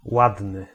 Ääntäminen
IPA: [ˈvak.ɛr]